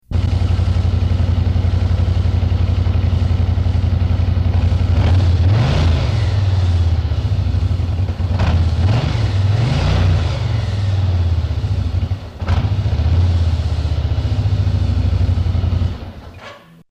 What you see above are waveforms recorded digitally on a Sony Minidisk recorder/player with the microphone 25 feet /files/includes/images/vh_shh_soundmeter_fullview.jpg (136488 bytes)away from the bike, a standard distance used by the DOT and many police agencies to determine whether you're exceeding the 85 decibel noise limit.
Everything was recorded at exactly the same level.
Nomad Exhaust Completely Stock   It's unlikely the sound from your speakers will sound exactly like the real bike but you can use these sound files for comparison purposes
vh_shhh_nomad_exhaust_stock.mp3